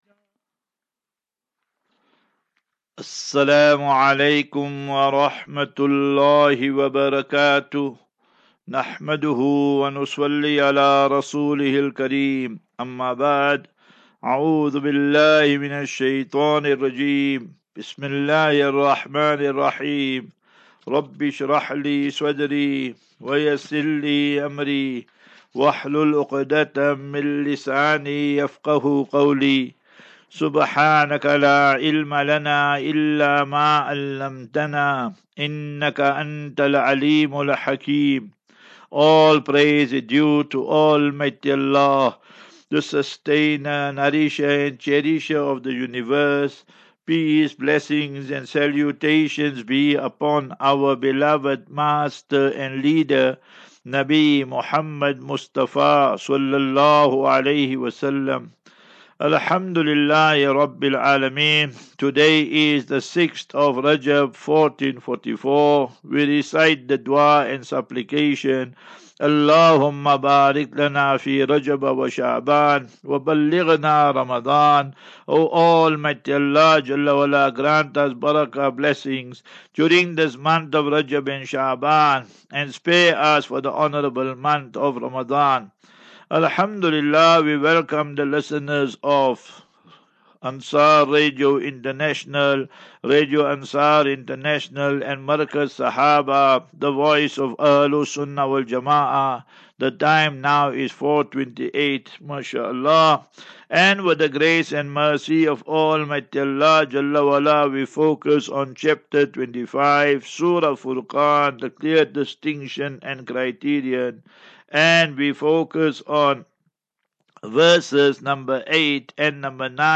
View Promo Continue Install As Safinatu Ilal Jannah Naseeha and Q and A 28 Jan 28 Jan 23- Assafinatu-Illal Jannah 39 MIN Download